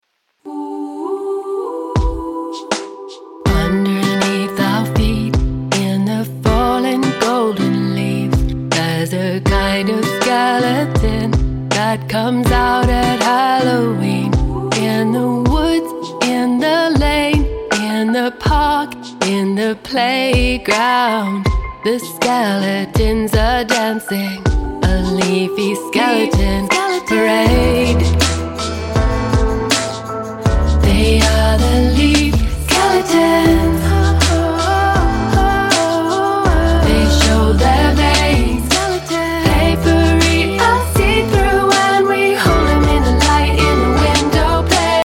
With three fun and silly songs for Halloween
not-too-scary spooky season EP